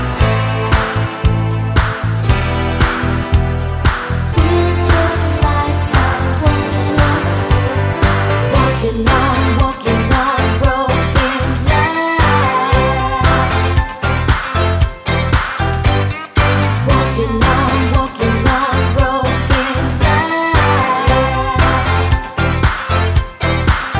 End Cut Down Pop (1990s) 4:06 Buy £1.50